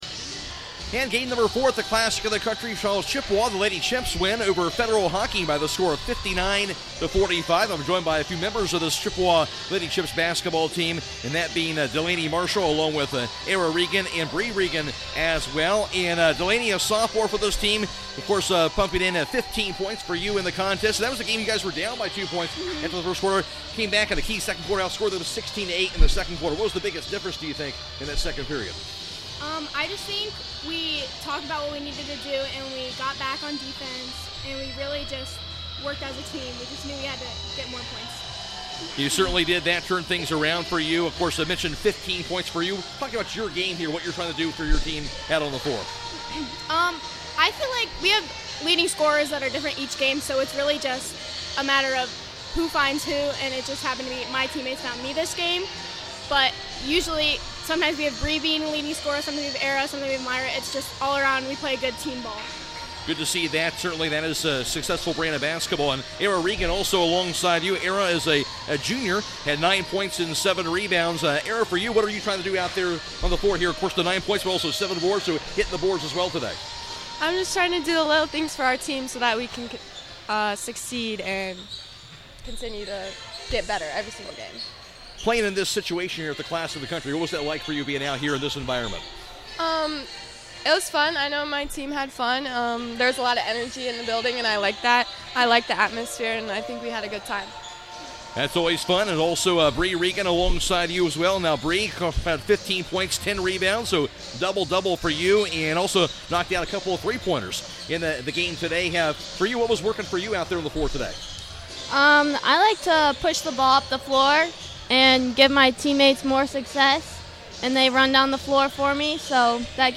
2025 Classic In the Country – Chippewa Player Interviews